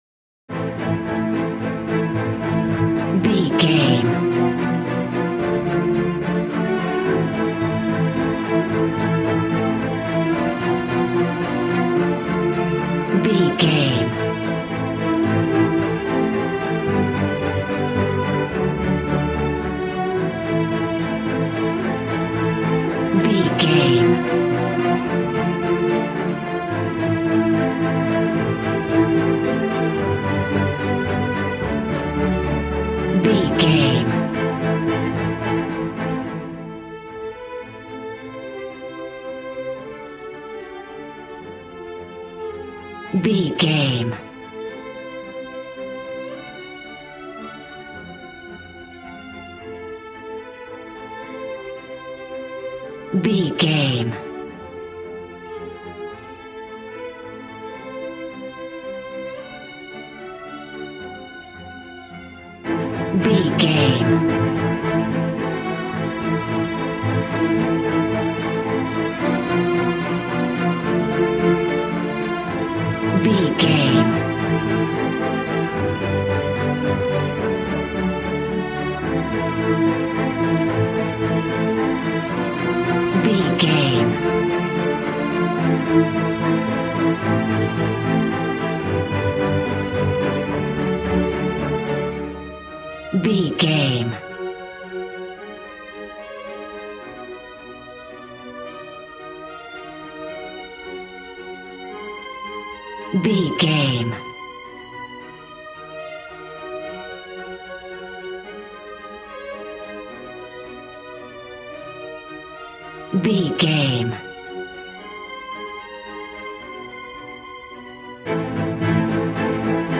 Regal and romantic, a classy piece of classical music.
Aeolian/Minor
regal
strings
brass